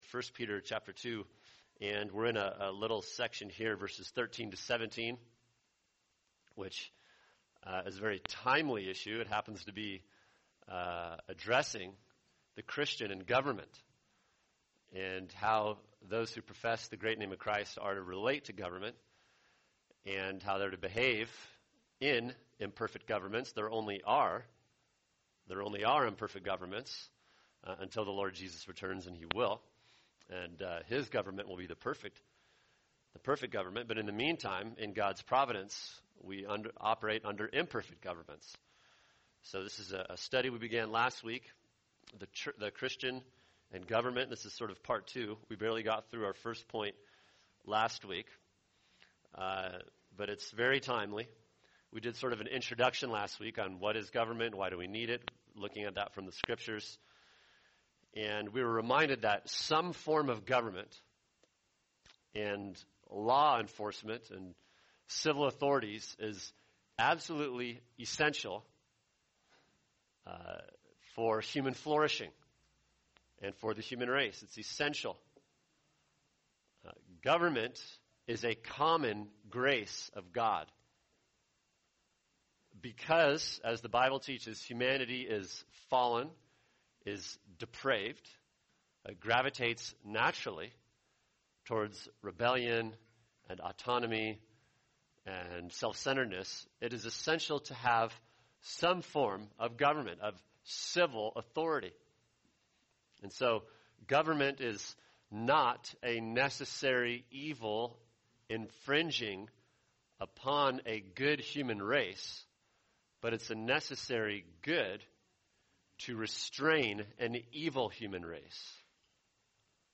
[sermon] 1 Peter 2:13-17 The Christian & Government – Part 2 | Cornerstone Church - Jackson Hole